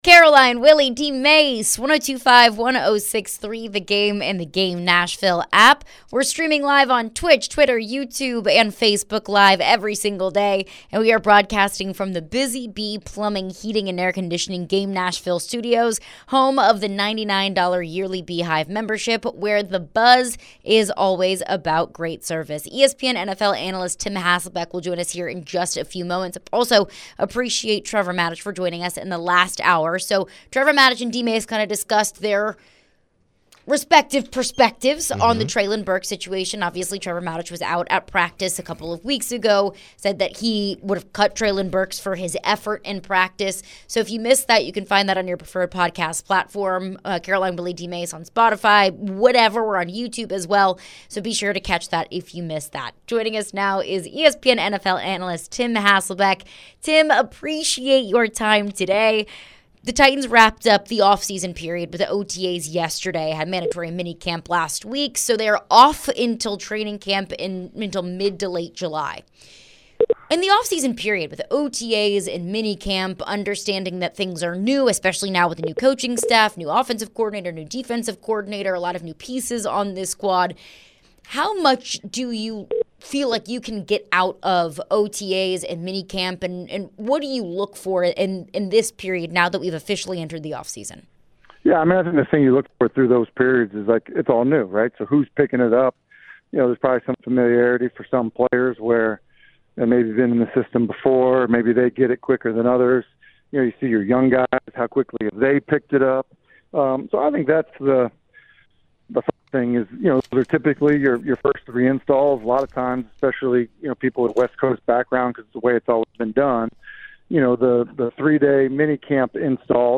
chat with ESPN NFL Analyst Tim Hasselbeck. Tim was asked about the Titans and if he had any concerns about the team. Should Titans fans be worried about Will Levis this offseason or T’Vondre Sweat?